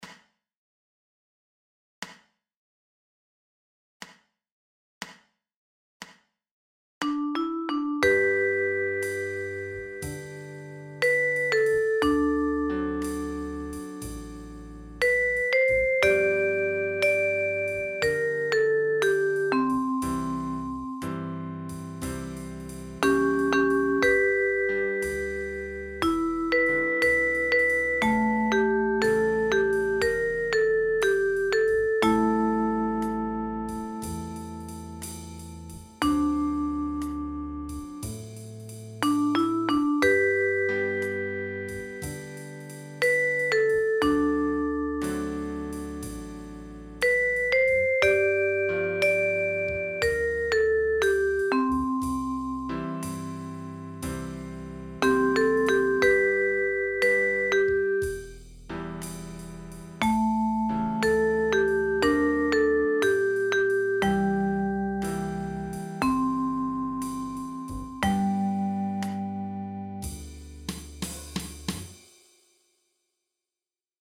12 Blues songs von 4 weiblichen Blues Legenden